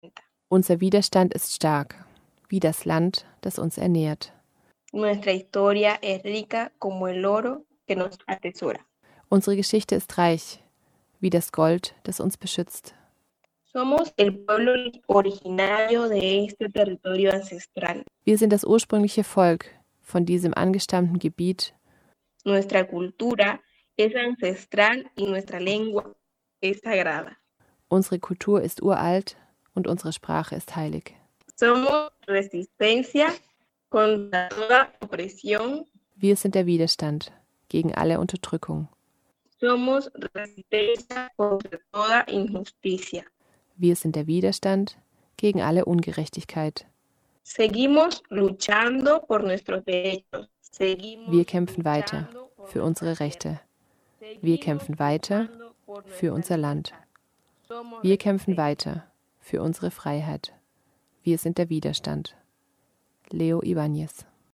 Gedicht.mp3